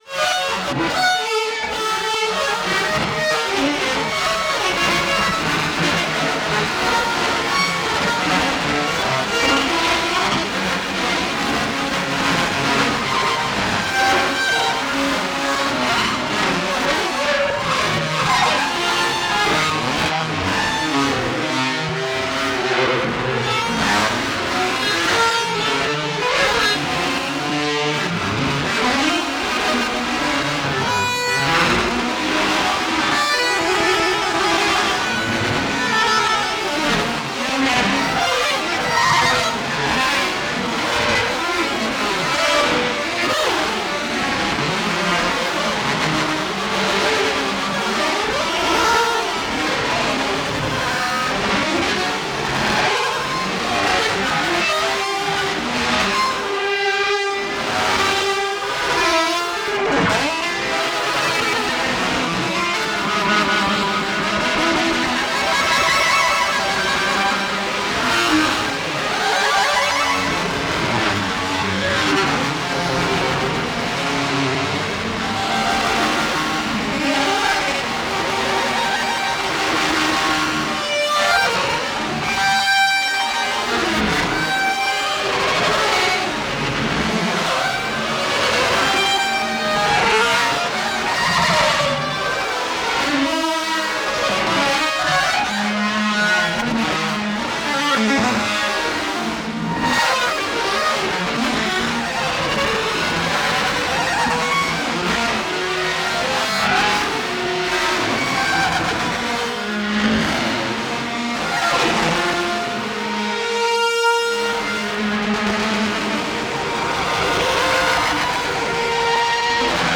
その艶かしい動きは、有機的に変動していく律動。
有機的で奔放な生命力溢れる即興の印象を与えます。
エロティックな機械の軋み、或はメカニカルな生命の息吹、その真逆のものが同一時空に美しいカオスを作っているような。
現代音楽、先端的テクノ、実験音楽をお好きな方々にはもちろん、ギター愛好家の方々にもお薦めのアルバムです。